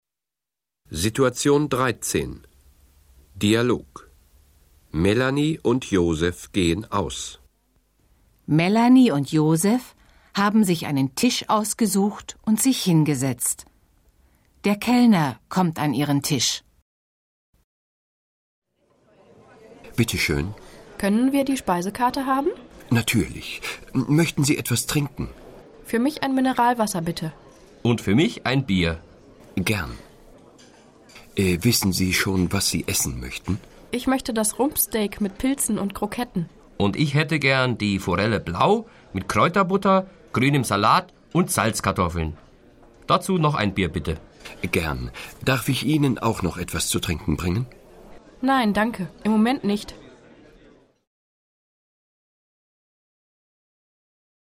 Situation 13 – Dialog: Melanie und Josef gehen aus (875.0K)